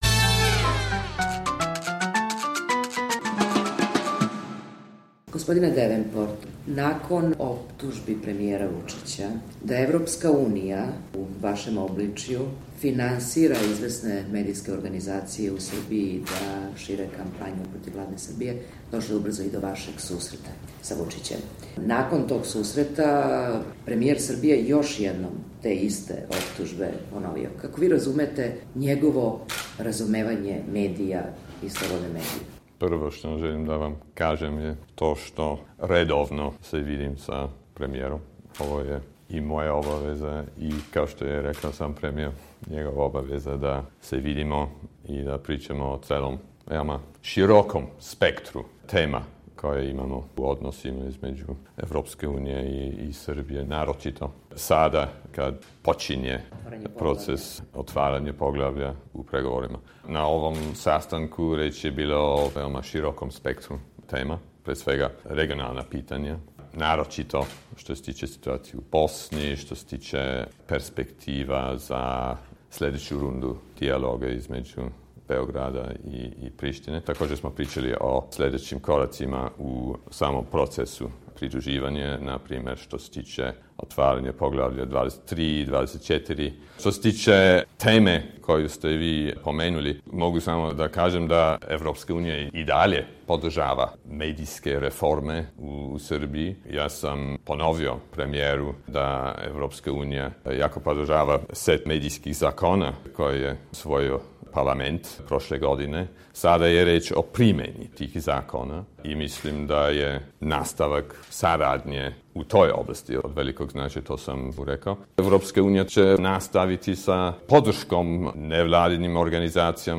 Intervju - Majkl Davenport